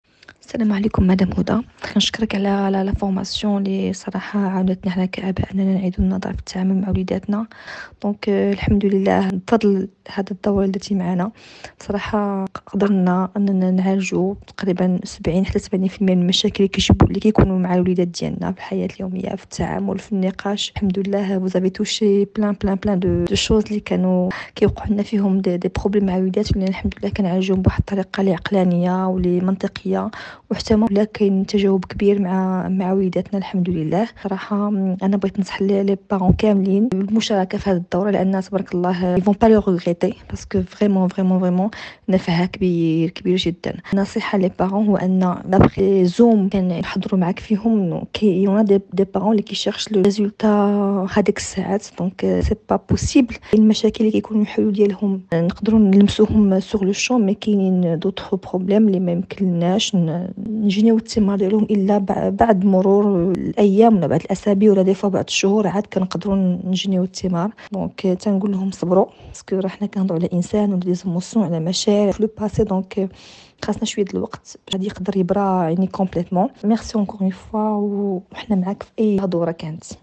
ماذا قال من شارك في الدورة